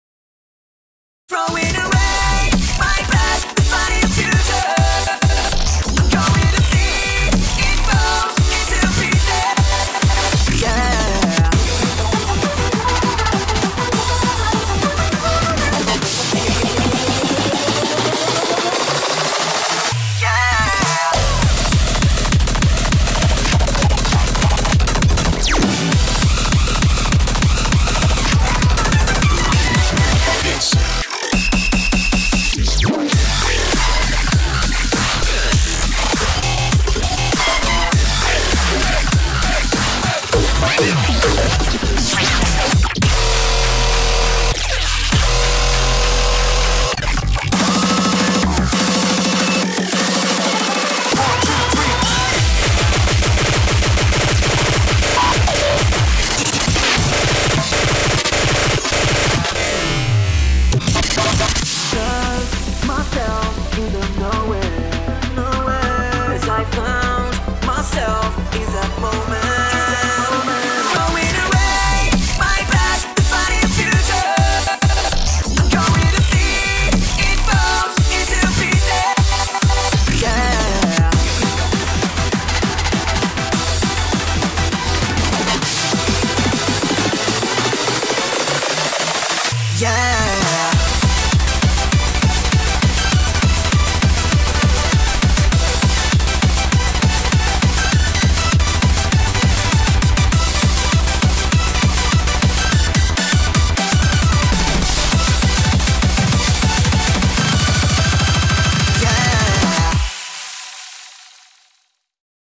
BPM50-200
Audio QualityPerfect (High Quality)